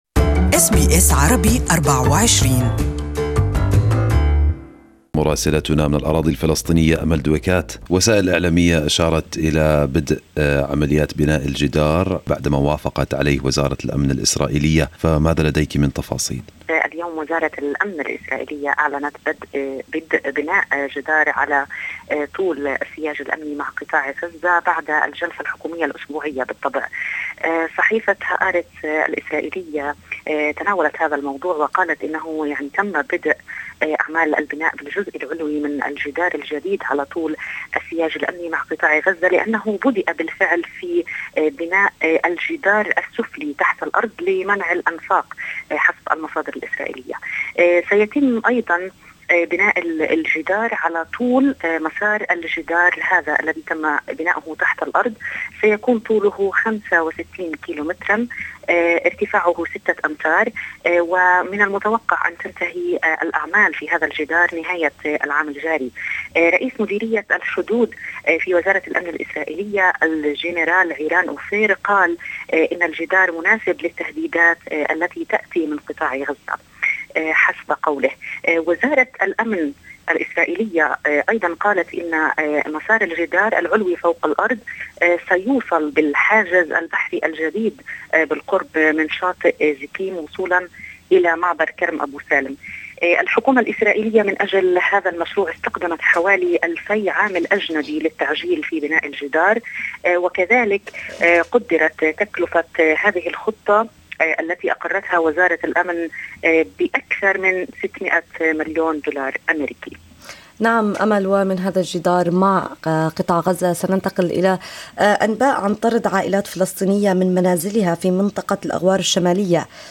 Weekly report from Ramallah, Palestinian Territories